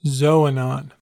A xoanon (/ˈz.ənɒn/
En-us-xoanon.ogg.mp3